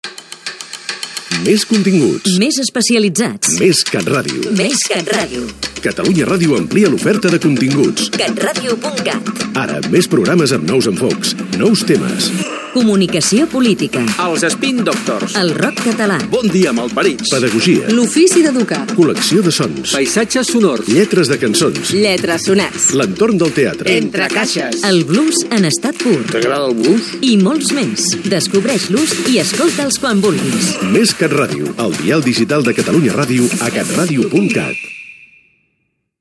Promoció del canal distribuït per Internet